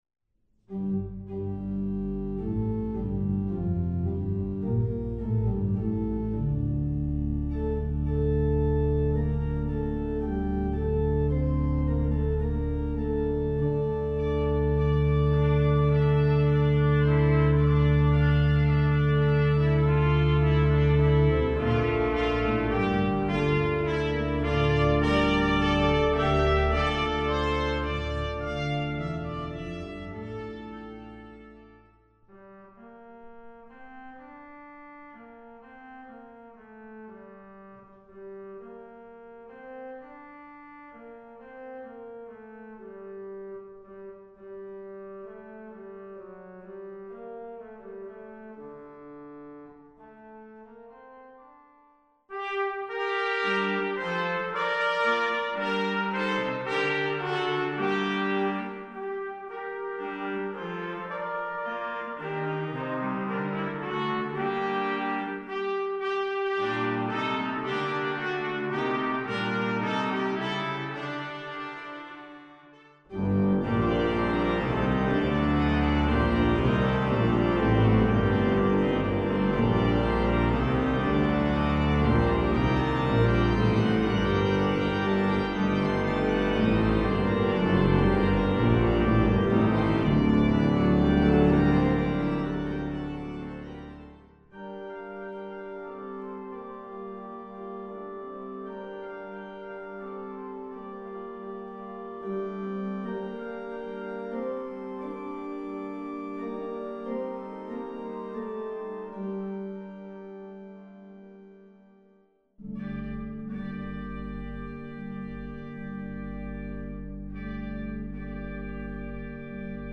Voicing: Trumpet Duet